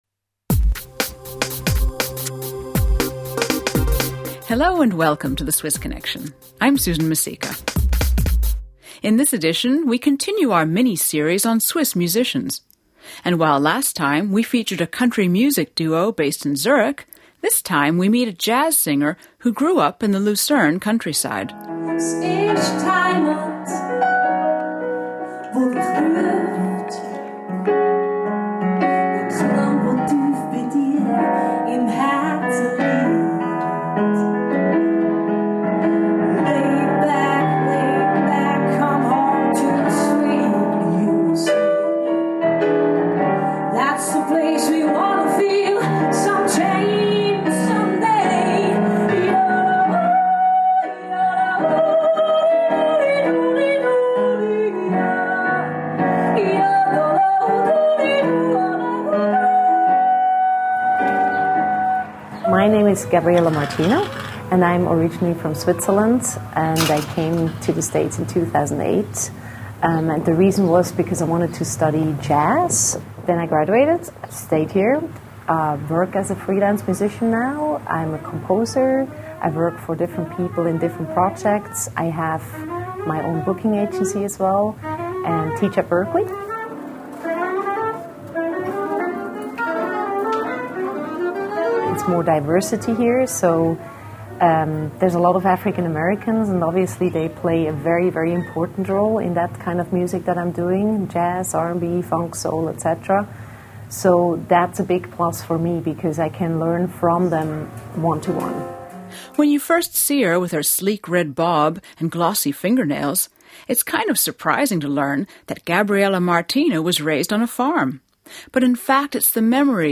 women talking and singing with jazz music in background